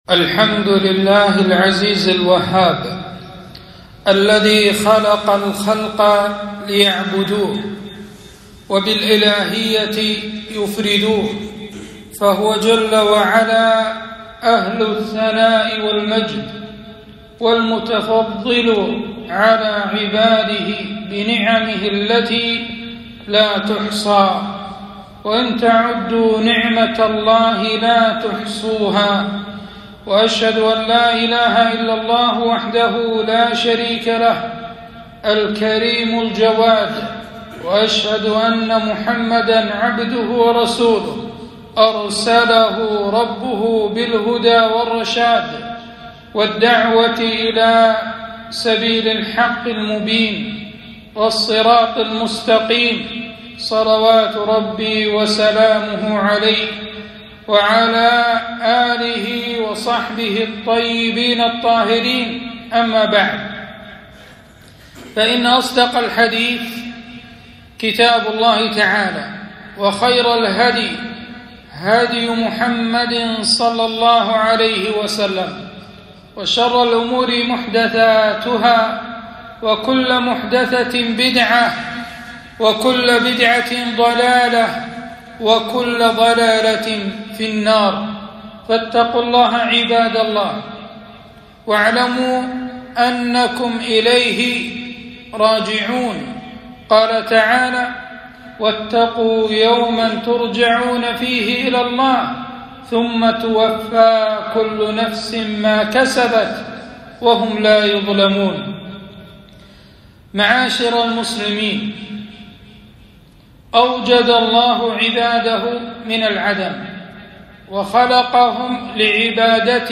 خطبة - العشرة الزوجية أحكامها وسننها وآدابها